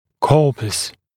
[‘kɔːpəs][‘ко:пэс]тело (в стомат. контексте тело нижней челюсти)